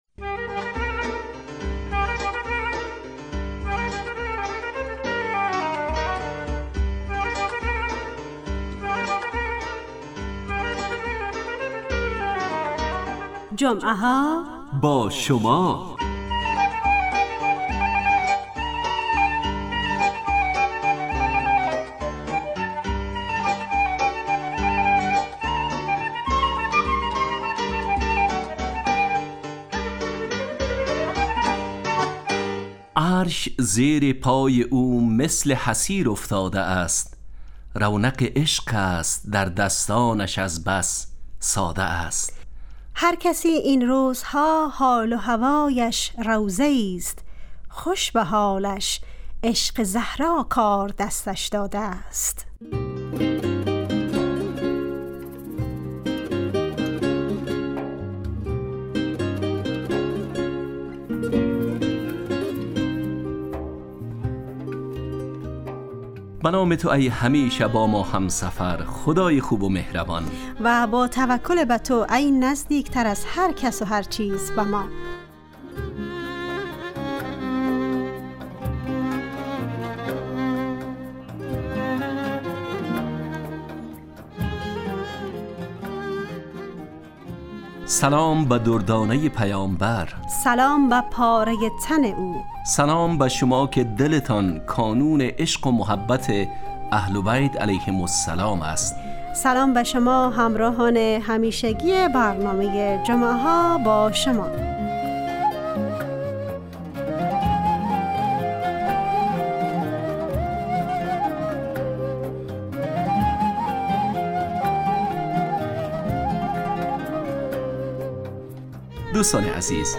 جمعه ها باشما برنامه ایست ترکیبی نمایشی که عصرهای جمعه بمدت 40 دقیقه در ساعت 17:15 دقیقه به وقت افغانستان پخش می شود و هرهفته به یکی از موضوعات اجتماعی...